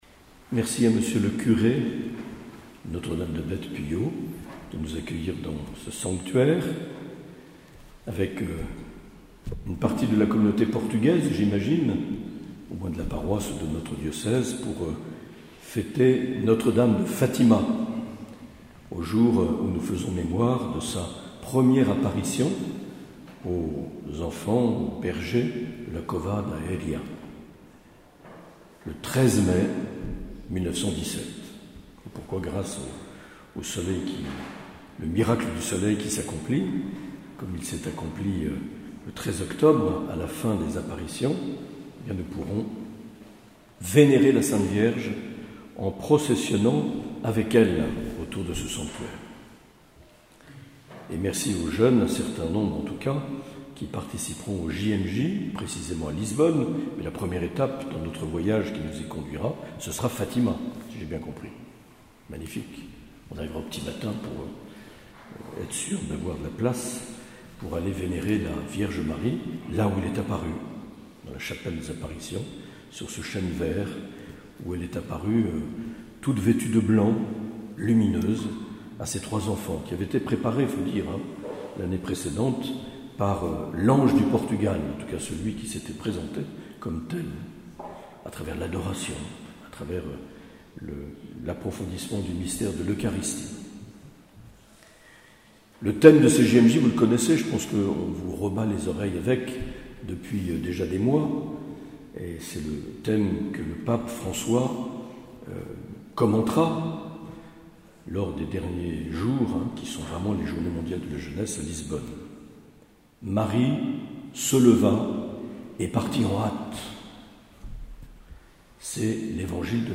Les Homélies
Une émission présentée par Monseigneur Marc Aillet